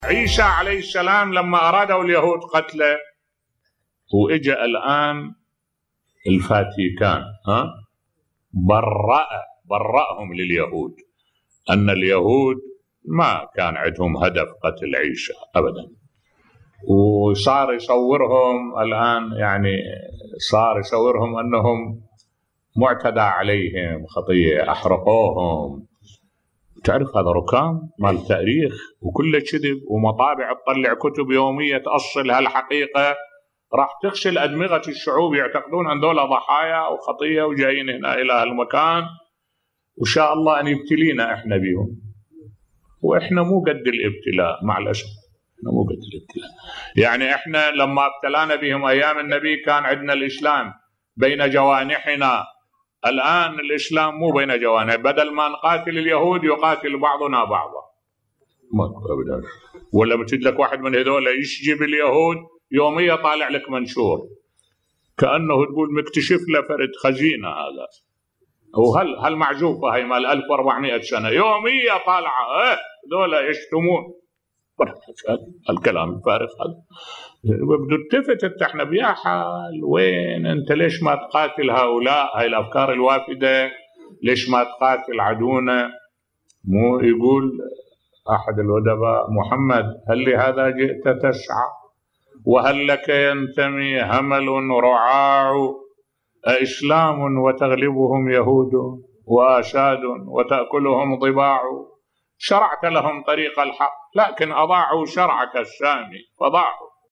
ملف صوتی الله امتحننا بالعدو الصهيوني ولكن هل نجحنا بصوت الشيخ الدكتور أحمد الوائلي